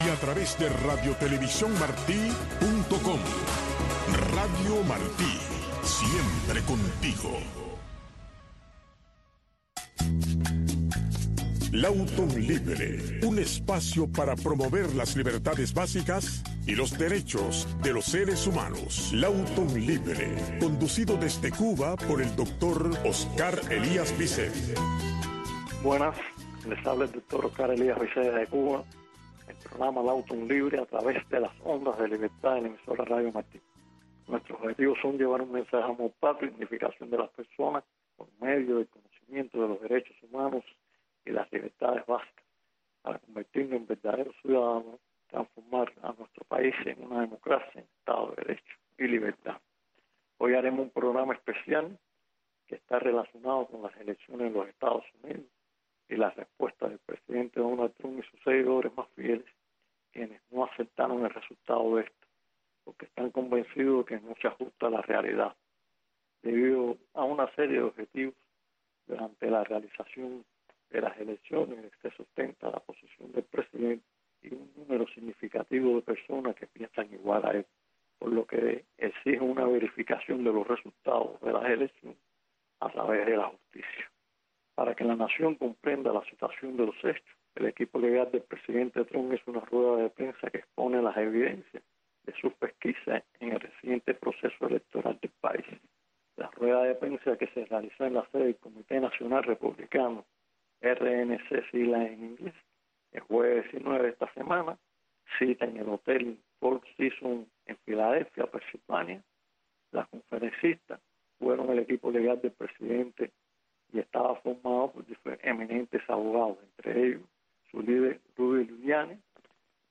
Lawton Libre es el programa conducido por el Doctor Oscar Elías Biscet que te habla de los derechos humanos, de las libertades básicas y de cómo lograr la libertad, tu libertad, porque si aprendes a ser libre todos los seremos Todos los sábados a las 7 am y también los sábados y domingos a las 11 de la noche en Radio Martí.